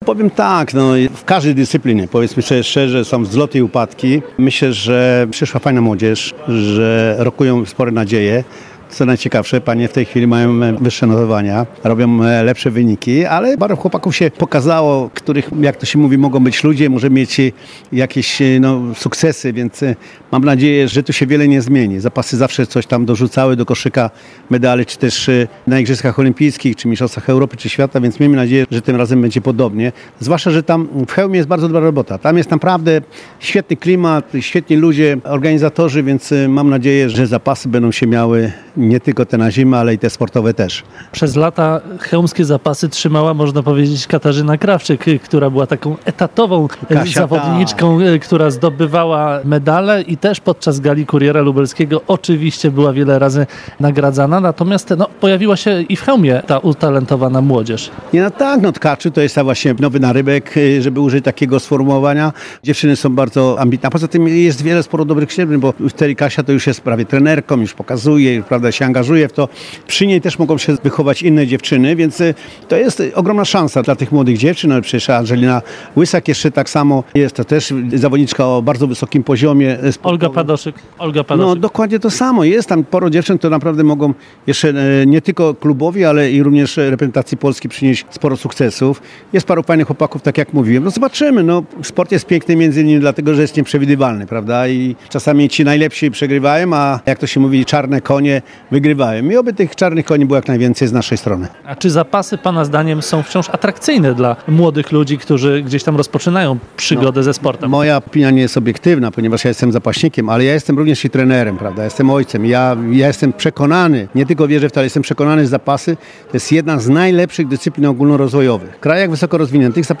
Jednym z gości gali 64. Plebiscytu Sportowego „Kuriera Lubelskiego” był prezes Polskiego Związku Zapaśniczego, a w przeszłości mistrz świata i srebrny medalista olimpijski w zapasach Andrzej Supron.